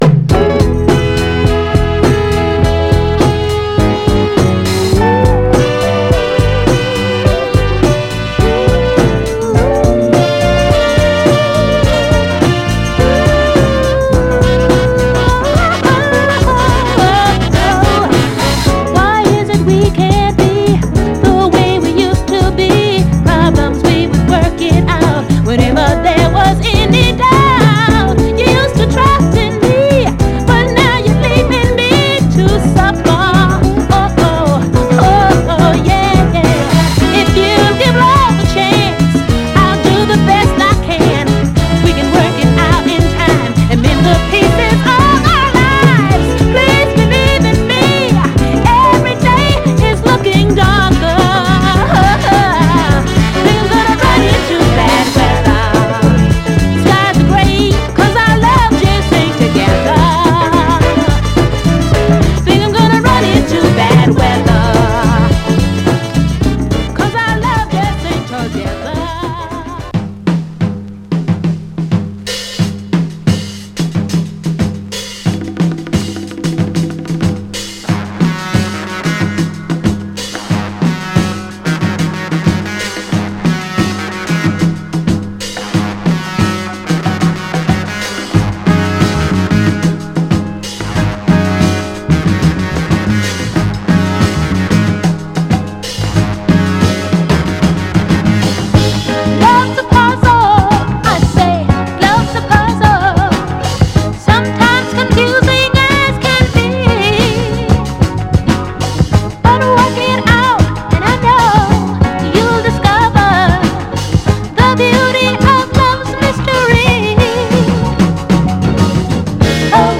盤はエッジ中心にごく細かいスレ、いくつか薄い線キズ箇所ありますが、グロスがありプレイ良好です。
※試聴音源は実際にお送りする商品から録音したものです※